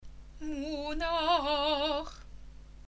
The sound of the Haftarot is the sound of forgiveness, even if the texts may be admonitions to call us to our tasks.
Munakh (Haftarah) [basic tune]